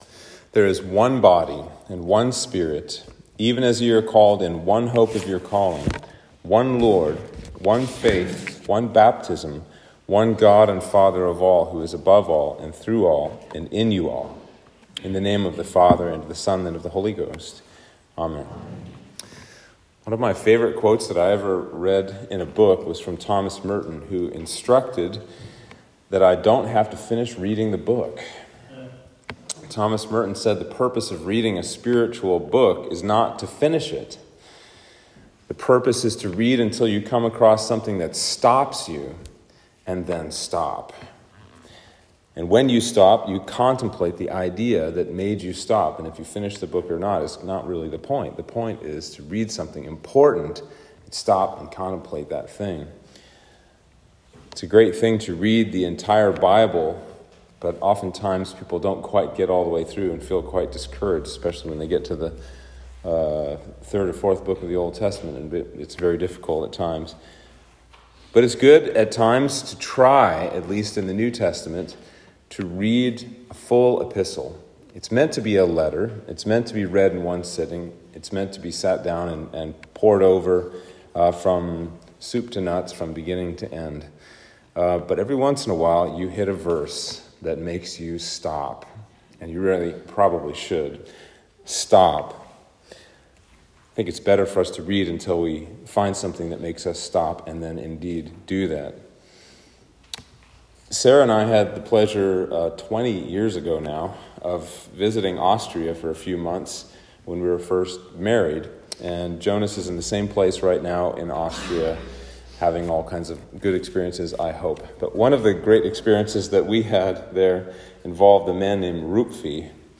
Sermon for Trinity 17